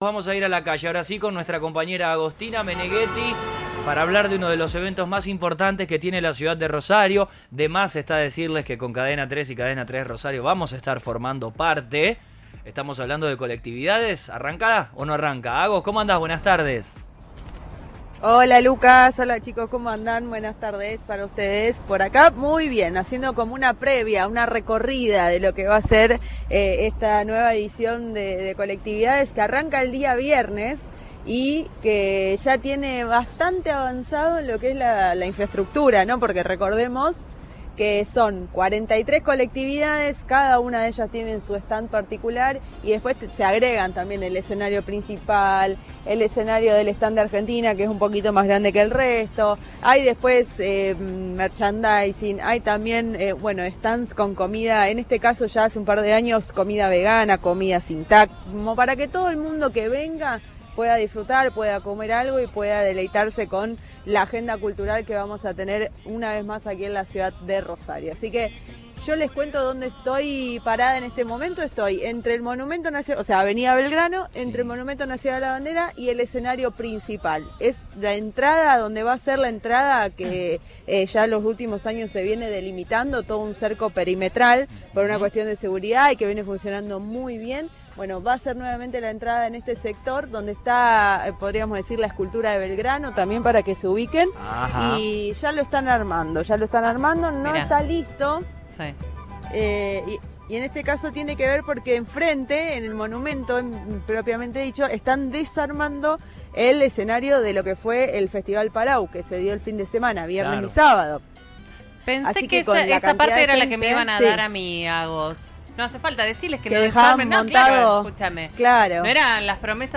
Desde la organización hablaron con Cadena 3 Rosario en plenos trabajos desarrollándose en el Parque Nacional a la Bandera, que comenzaron “con algo de atraso”, aunque “vamos a llegar bien”.